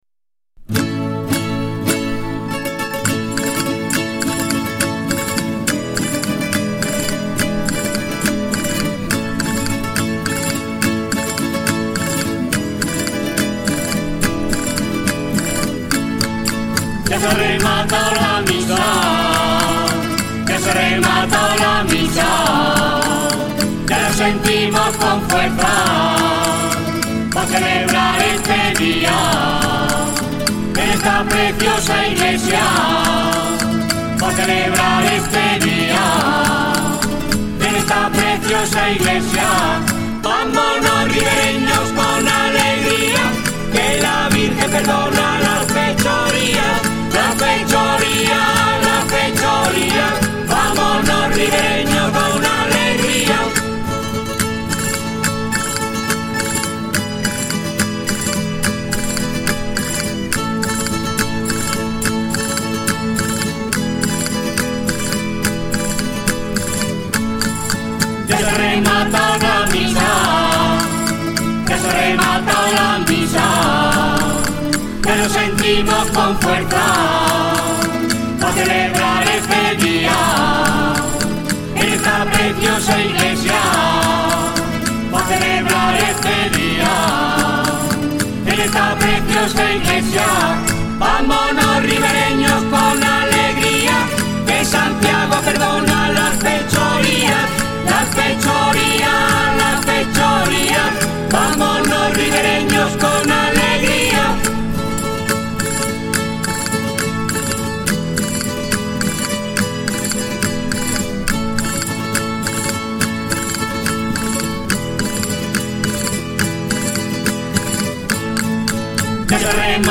11_Chipirrín_(jota) - Rondalla Santiago Apóstol Ver J_5.4.2
Rondalla Santiago Apostol
11_Canto_de_despedida_Jota.mp3